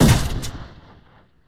Gun_Turret3.ogg